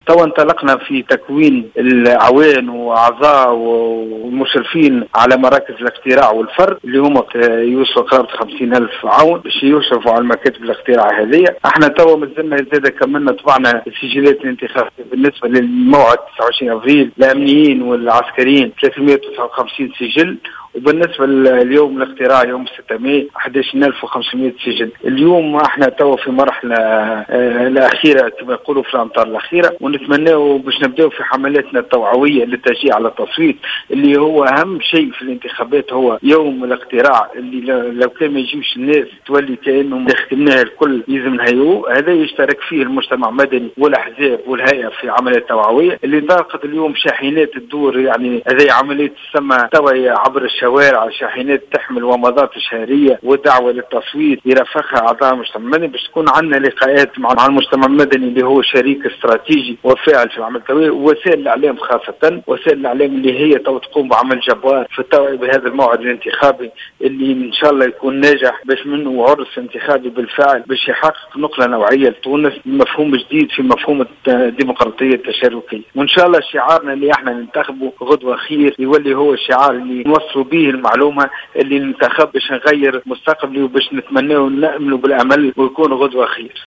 وأضاف في تصريح اليوم لـ"الجوهرة أف أم" أنه سيتم الانطلاق في الحملات التوعوية لحث المواطنين على التصويت والمشاركة في هذه الانتخابات.